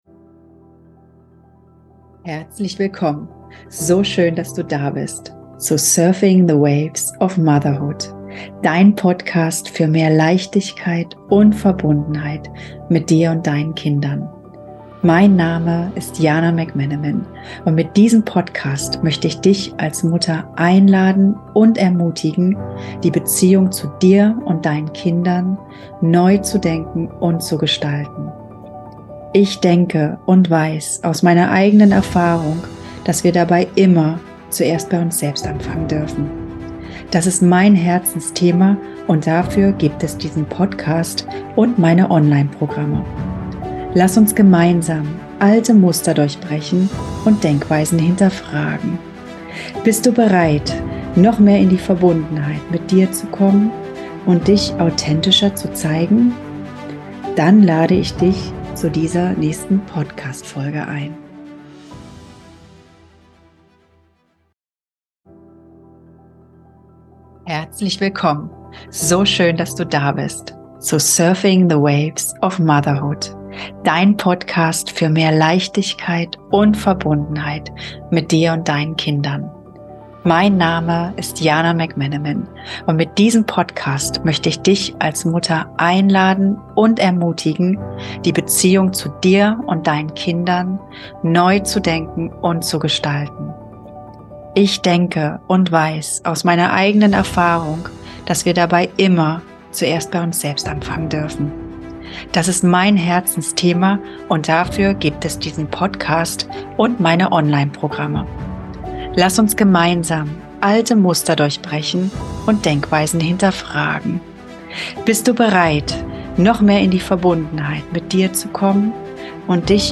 Ein berührendes Gespräch, das Vätern Mut macht und Müttern hilft, zu verstehen, was in ihren Partnern vorgeht.